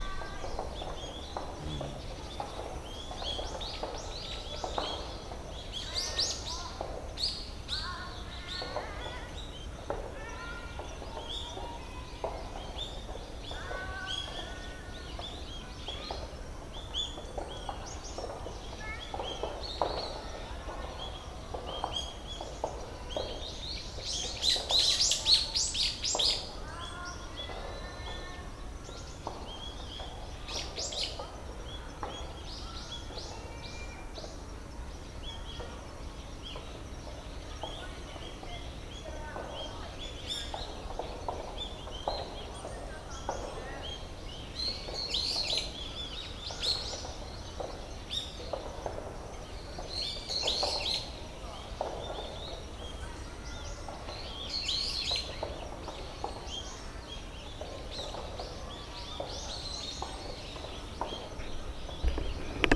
清瀬松山緑地保全地域で野鳥の録音、H2essentialとの録り比べ
清瀬松山緑地保全地域の東側入り口。
録音したファイルを、本体でノーマライズしました。
H1essential 内蔵マイク＋
ZOOM　ヘアリーウィンドスクリーン WSU-4